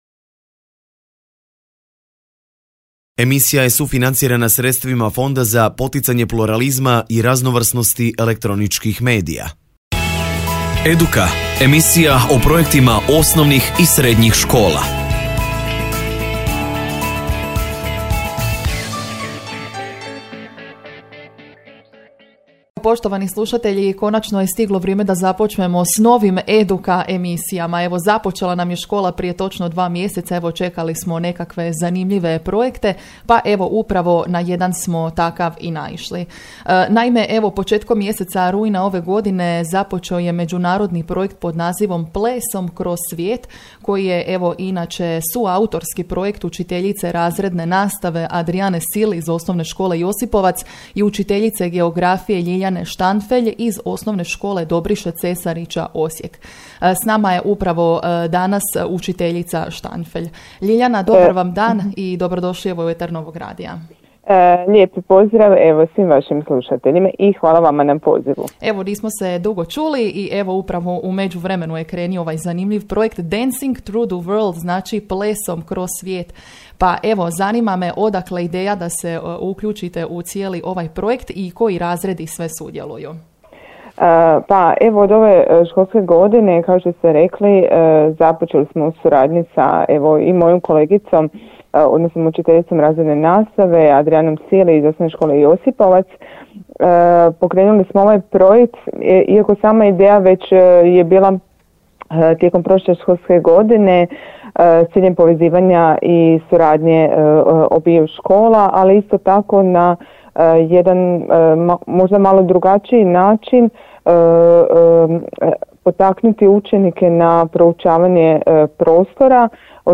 Audio snimku intervjua u cijelosti mo�ete poslušati u prilogu.